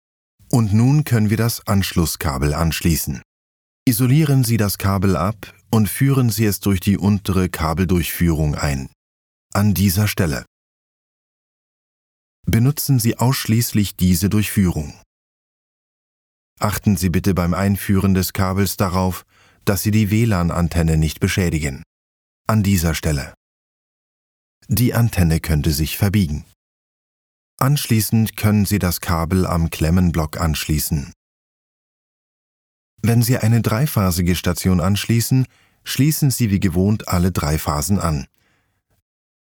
verfügt über eine tiefe, authentische deutsche Baritonstimme
E-Learning
Mikrofon: Neumann TLM 103 / Audiointerface: Solid State Logic (SSL-2+) / Popkiller: K&M 23956 / DAW: Cubase Elements 11 / Wavelab 11
Soundbooth: „Raum im Raum“